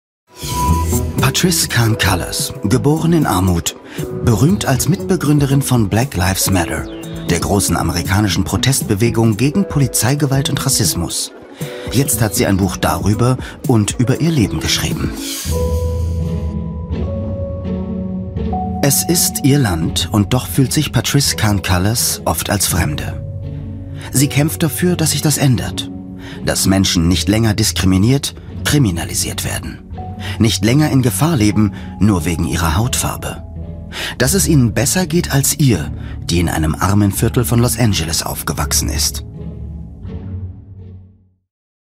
markant, dunkel, sonor, souverän
Mittel minus (25-45)
Comment (Kommentar), Doku, Off